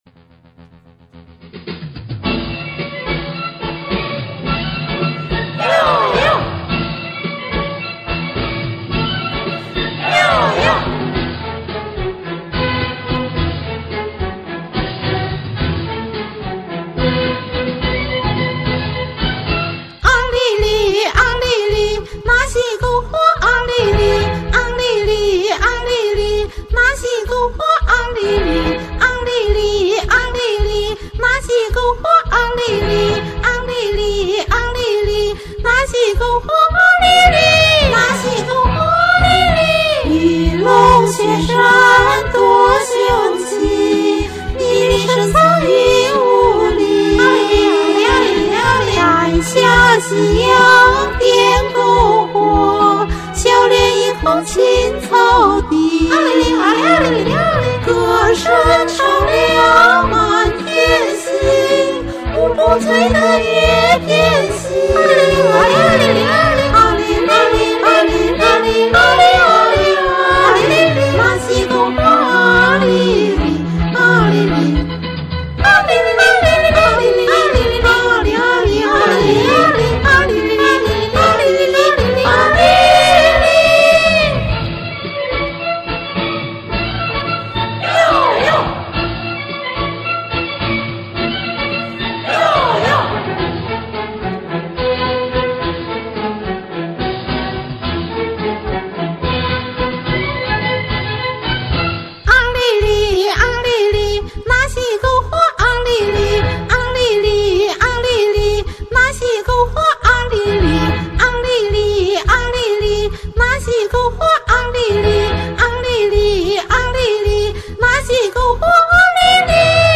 这首歌旋律优美，动感十足，歌词极具画面感。